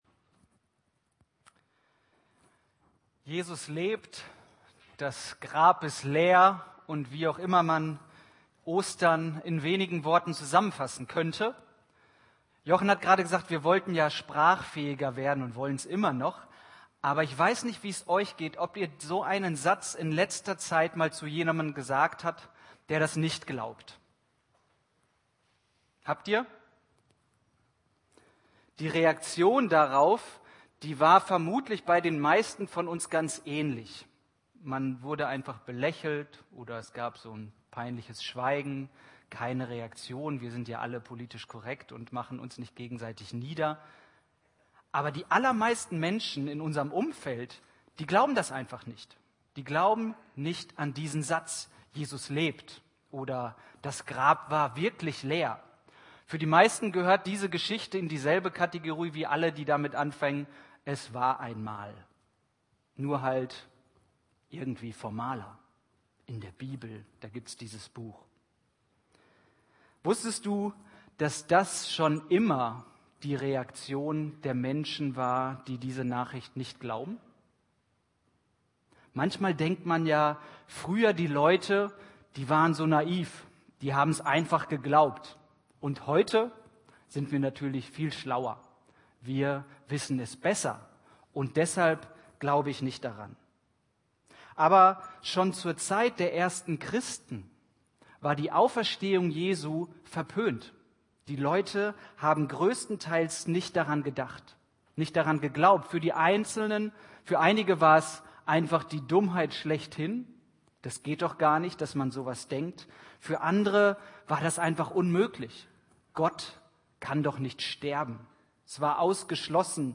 Predigt 1.Korinther Sie sehen gerade einen Platzhalterinhalt von YouTube .